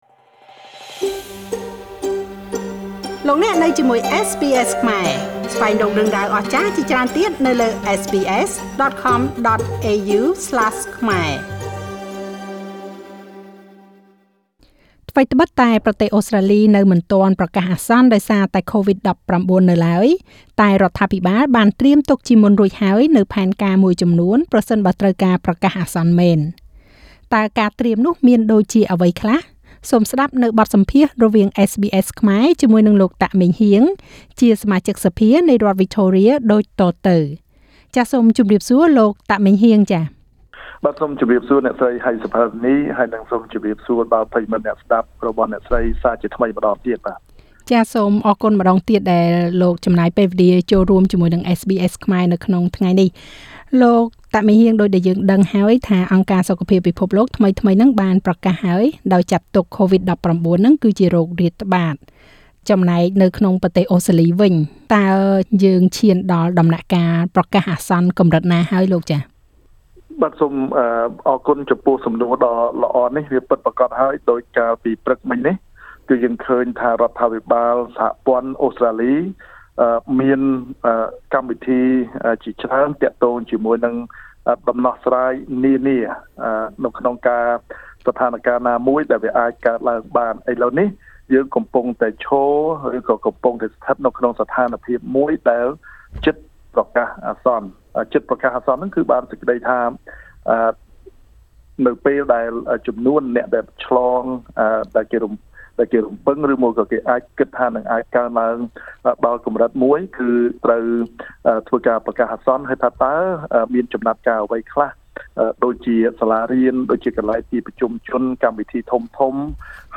ថ្វីត្បិតតែអូស្រ្តាលីនៅមិនទាន់ប្រកាសអាសន្នដោយសារតែ Covid-19 តែរដ្ឋាភិបាលបានត្រៀមទុកជាមុនរួចហើយ នូវផែនការមួយចំនួនប្រសិនបើចាំបាច់ត្រូវប្រកាសអាសន្នមែននោះ។ លោក តាក ម៉េងហ៊ាង សមាជិកសភានៃរដ្ឋវិចថូរៀផ្តល់បទសម្ភាសន៍ជាមួយនឹងSBSខ្មែរអំពីរឿងនេះ។